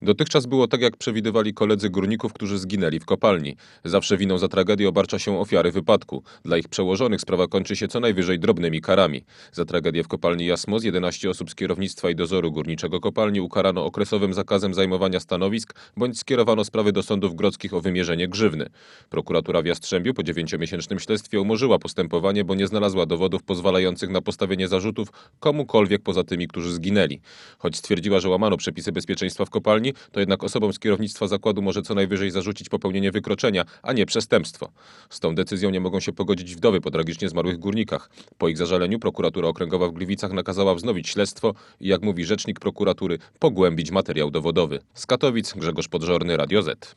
Słuchaj naszego reportera